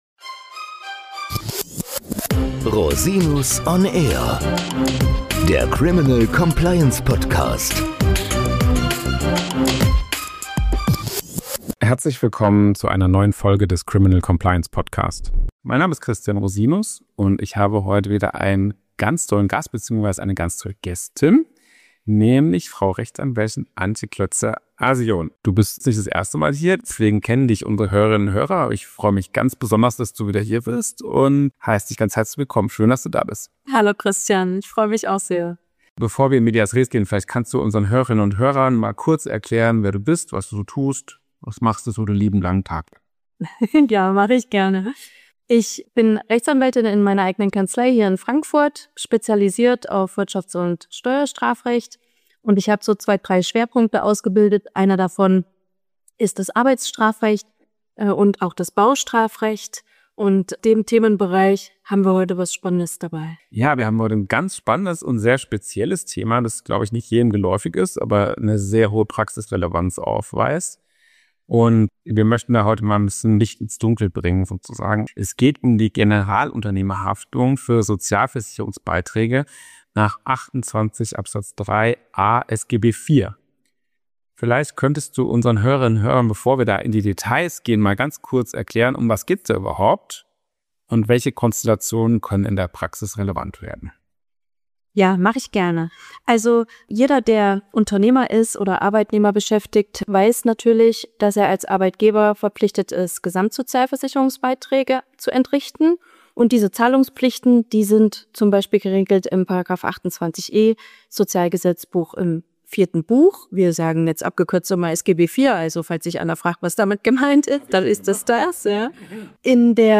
Generalunternehmerhaftung im Baugewerbe – Interview